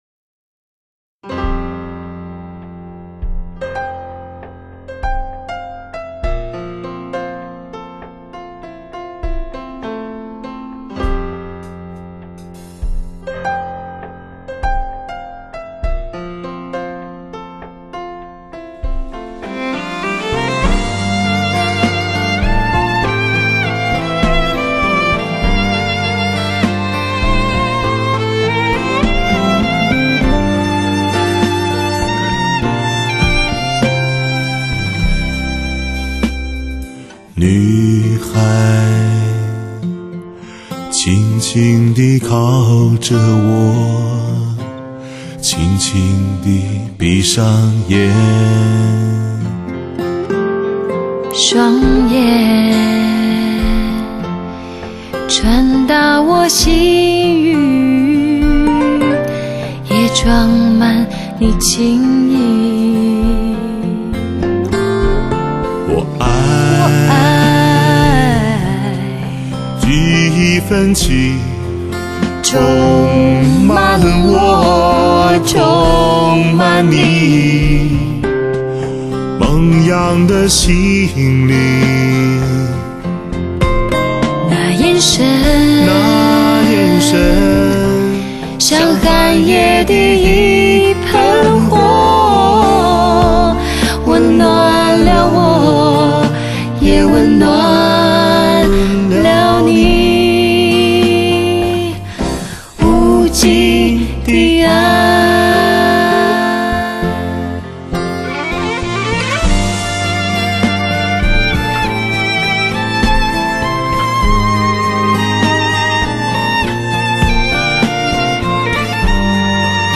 悠悠醇厚的至真歌喉